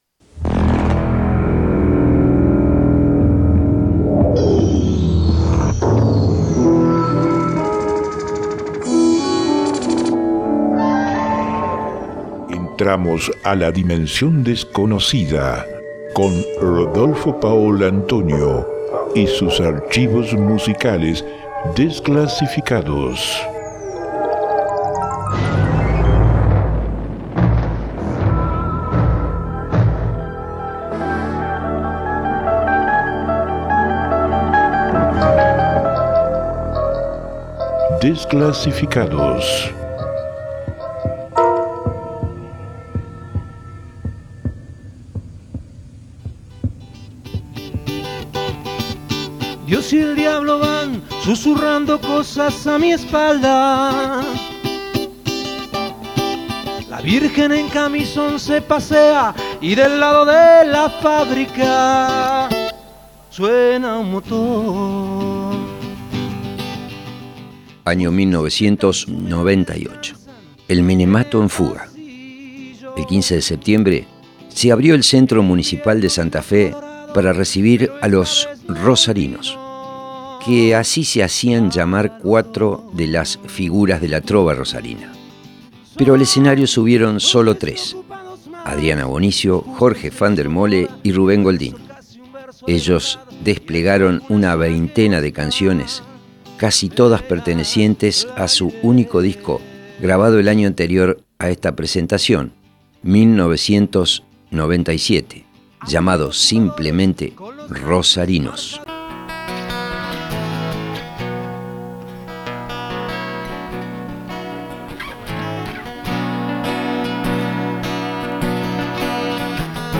en el Centro Cultural Municipal Sta.Fe